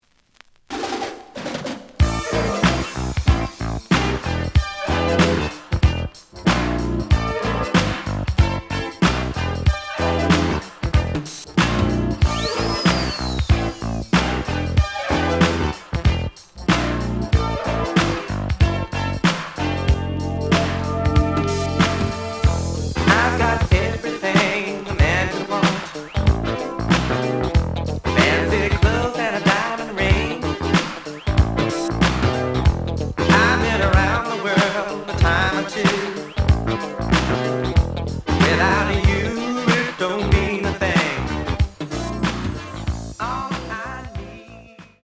The audio sample is recorded from the actual item.
●Genre: Disco
Edge warp. But doesn't affect playing. Plays good.)